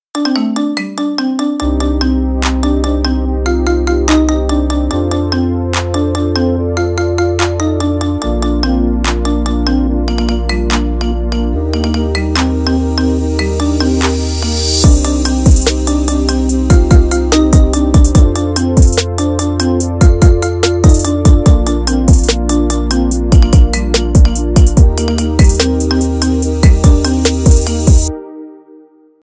Marimba Now remix